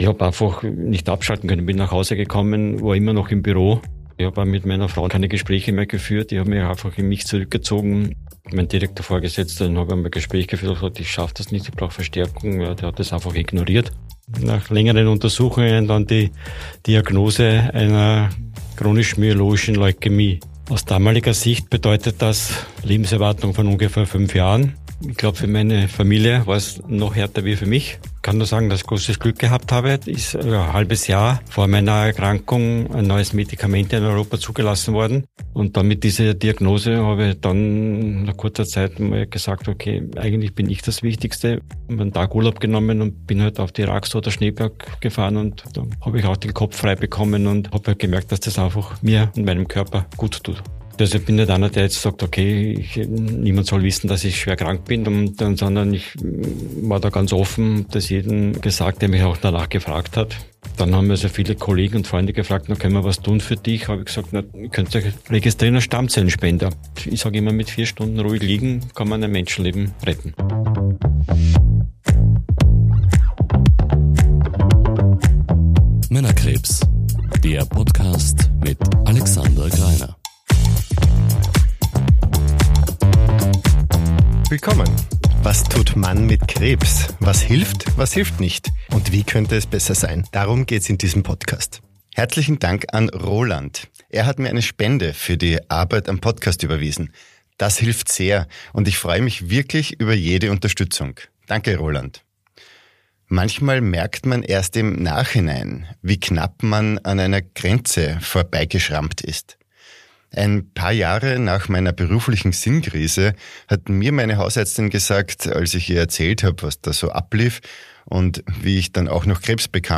Gespräch) · Folge 27 ~ Männerkrebs – Was tut Mann mit Krebs?